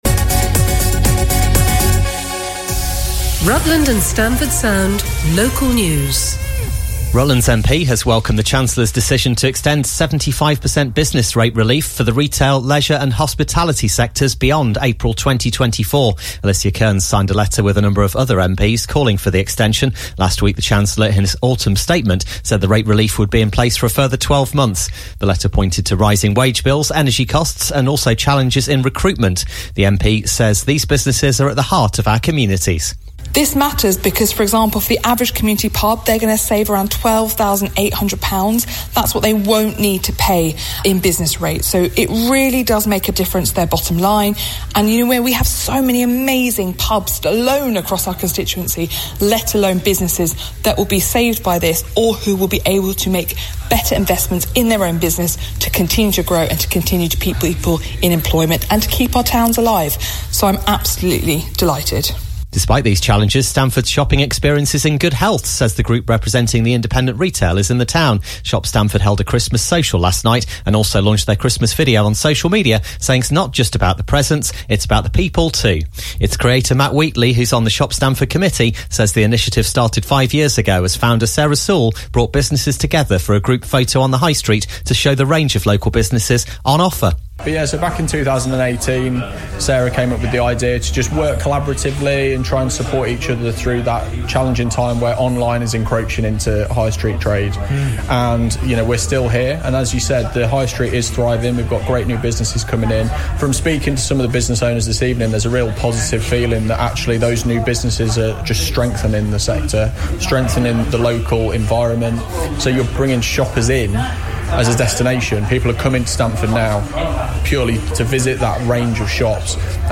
To hear these interviews in full, along with the rest of the morning's news bulletin, please click on the Play button: